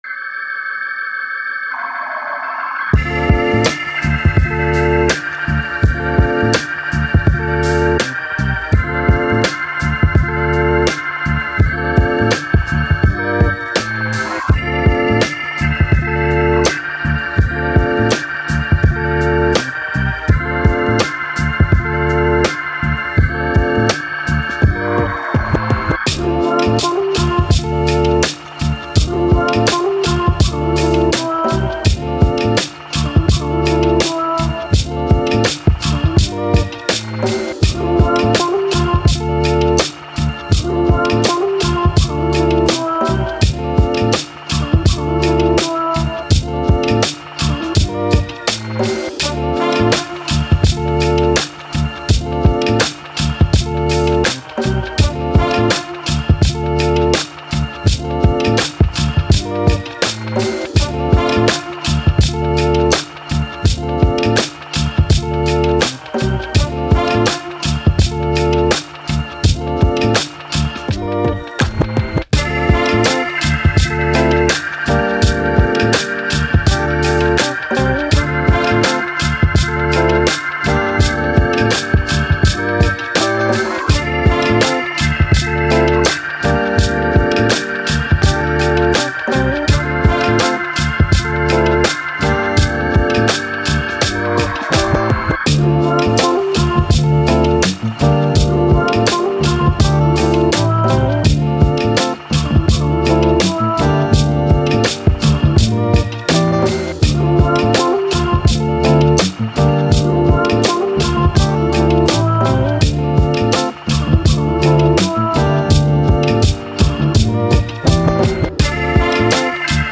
Hip Hop, Boom Bap, Journey, City